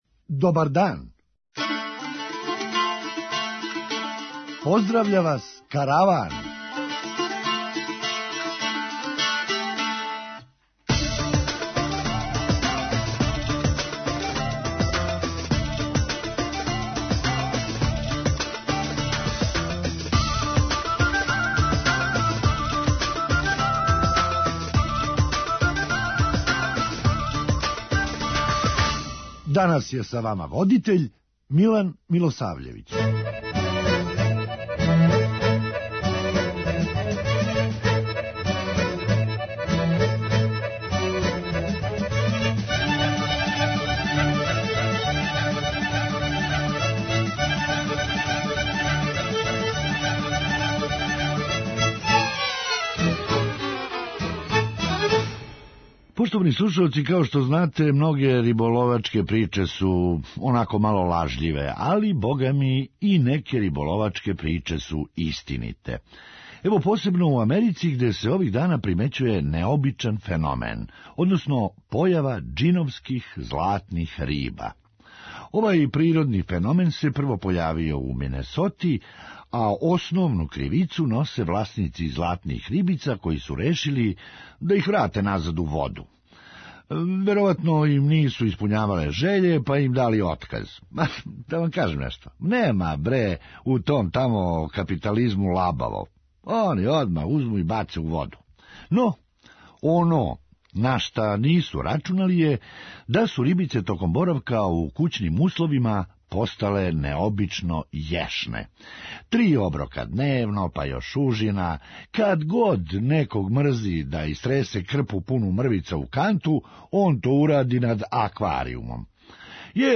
Хумористичка емисија
Био је то мали корак за човечанство, али велики за ципеле принца Чарлса. преузми : 9.21 MB Караван Autor: Забавна редакција Радио Бeограда 1 Караван се креће ка својој дестинацији већ више од 50 година, увек добро натоварен актуелним хумором и изворним народним песмама.